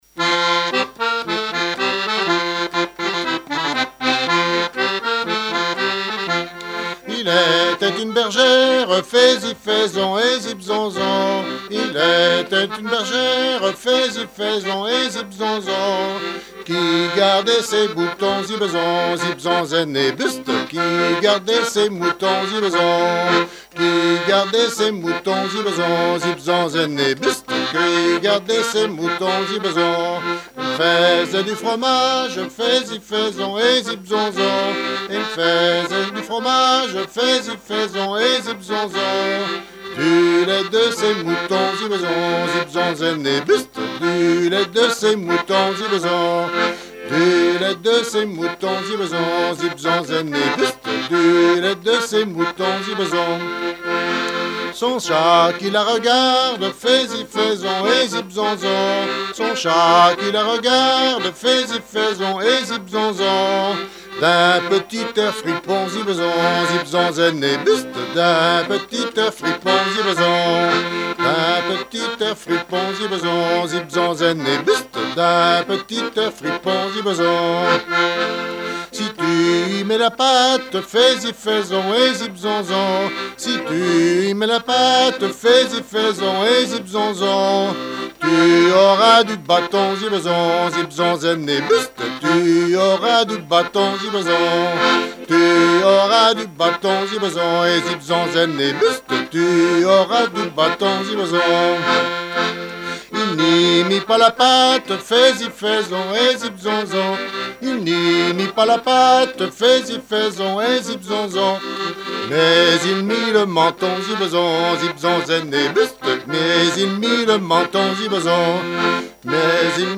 Genre laisse
répertoire de chansons, de danses et fables de La Fontaine